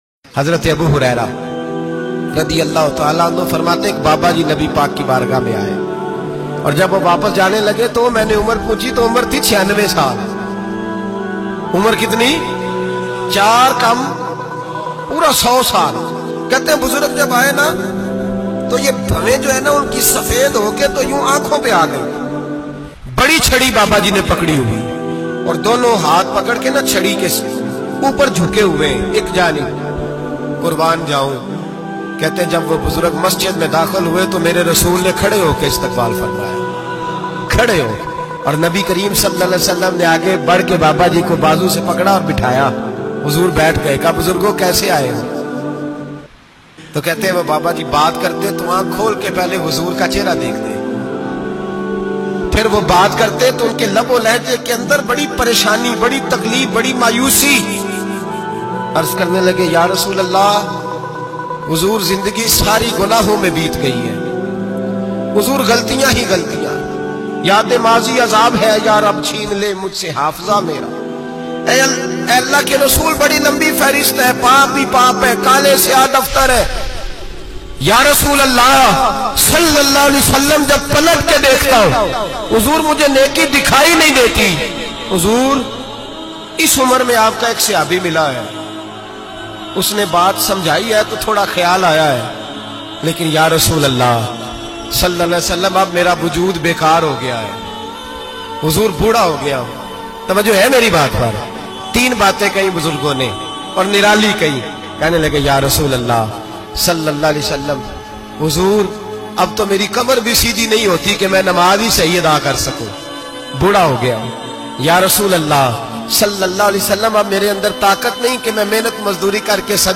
96 sala buzurg jab masjid tashreef lahy bayan mp3